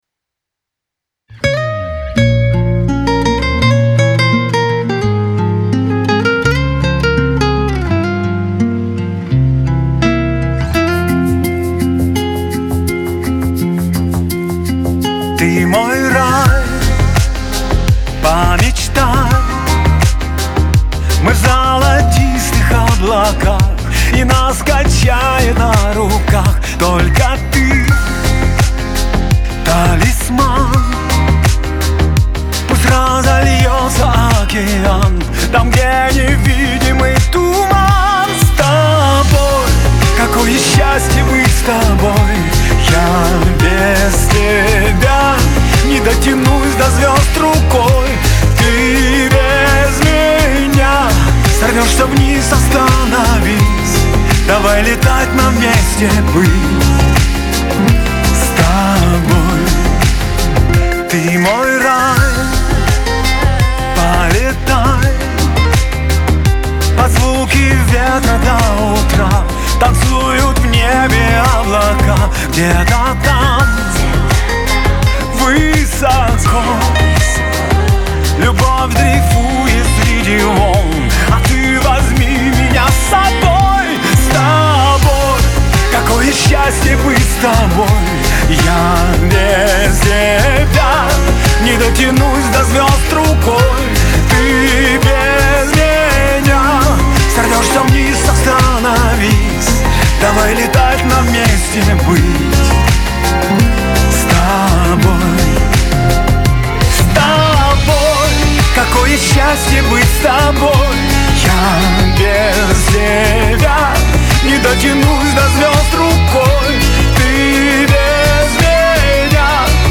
эстрада , диско